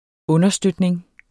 Udtale [ -ˌsdødneŋ ]